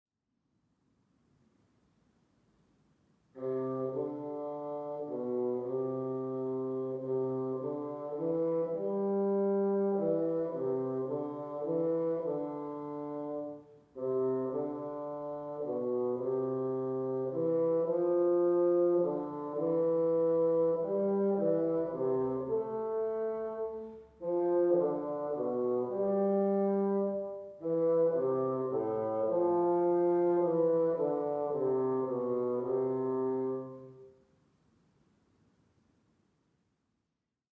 Julius Weissenborn Bassoon Studies, Opus 8, Vol 1, page 3, Tenuto, no 5